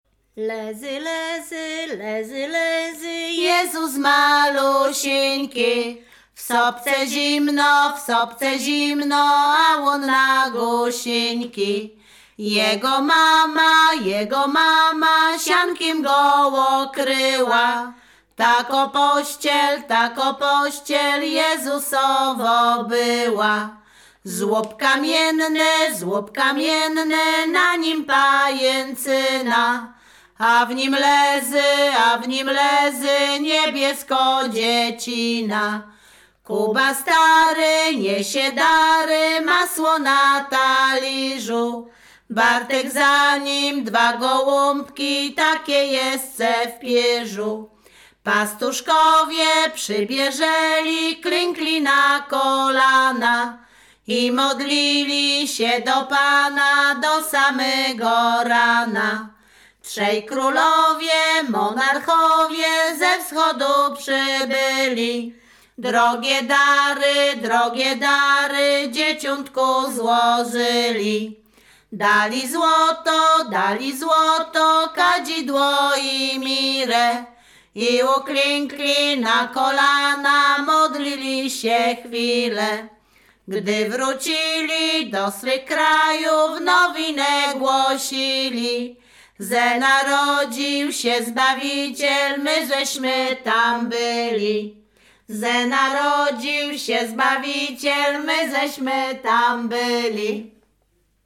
Śpiewaczki z Chojnego
województwo łódzkie, powiat sieradzki, gmina Sieradz, wieś Chojne
Kolęda
Nagrania w ramach projektu GPCKiE w Plichtowie pt. "Żywa pieśń ludowa. Śpiewy Wzniesień Łódzkich"